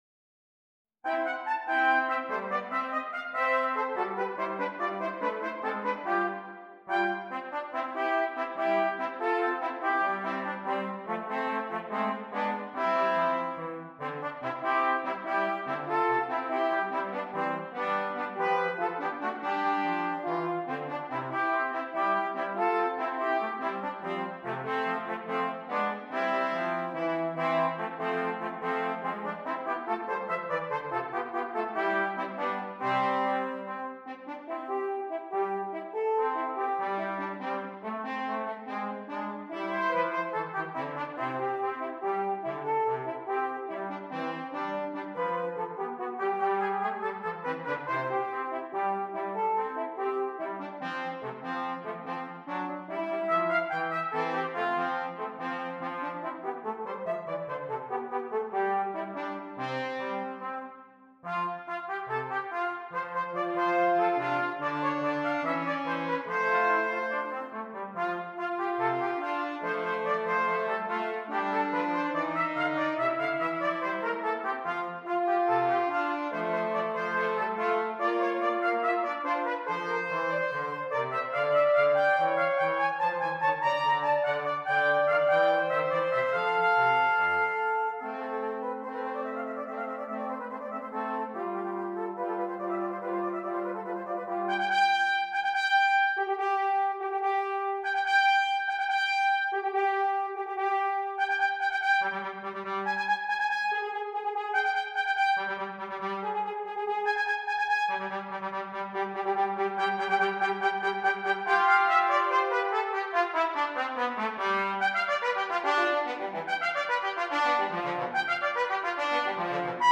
Brass Trio
a fun but challenging brass trio